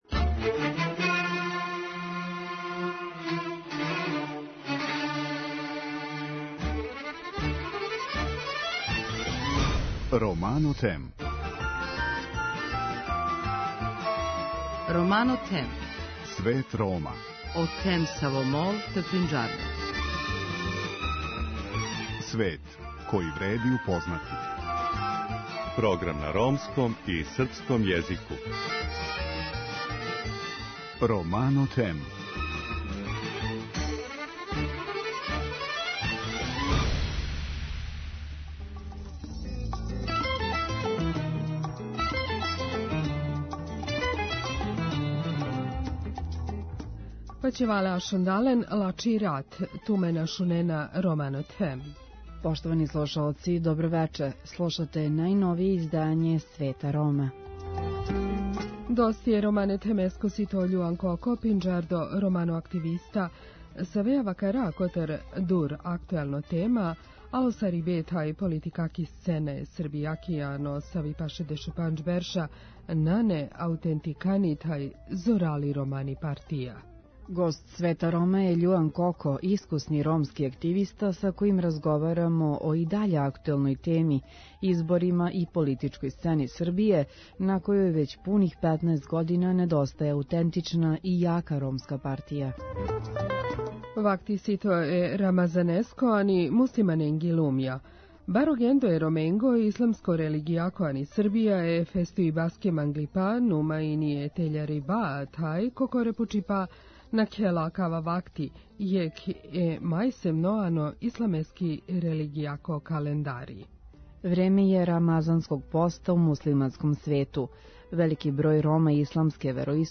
искусни ромски активиста, са којим разговарамо о, и даље актуелној теми, изборима и политичкој сцени Србије, на којој већ пуних 15 година недостаје аутентична и јака ромска партија.